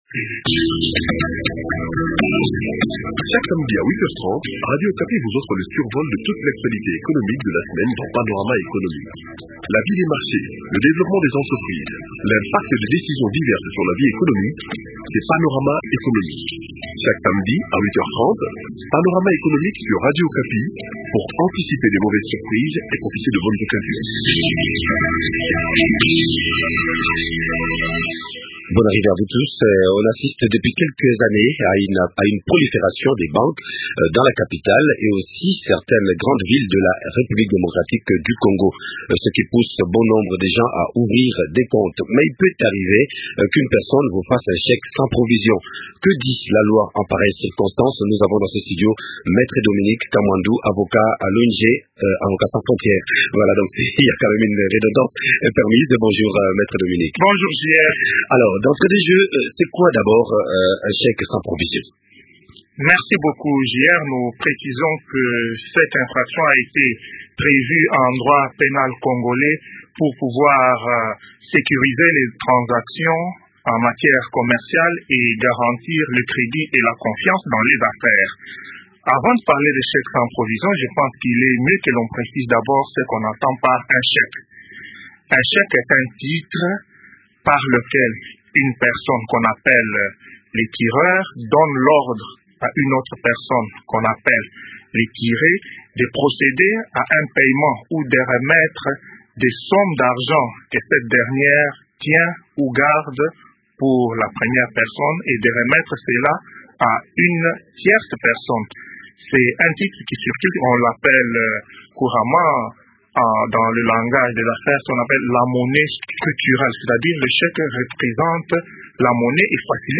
entretient